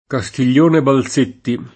Castiglion che Dio nol sa [kaStil’l’1j ke dd&o nol S#+] top. (Tosc.)